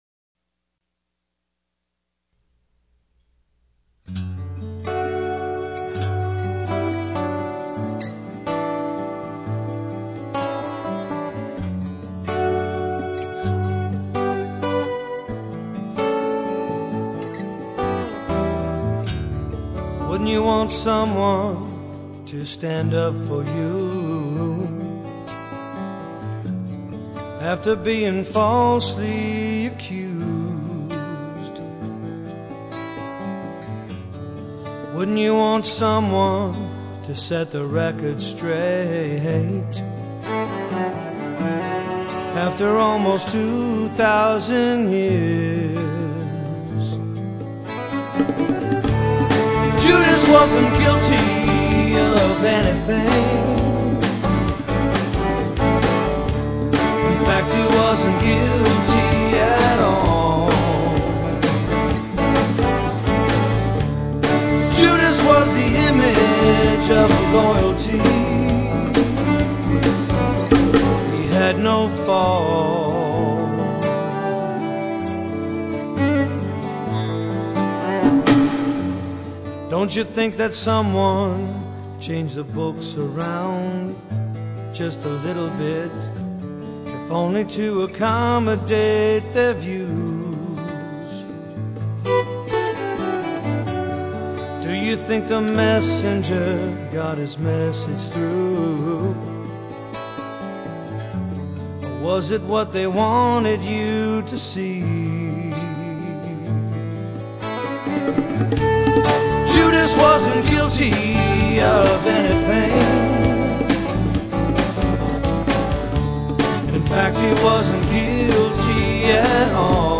Guitar/ Vocals /bass /synthesizer /congas .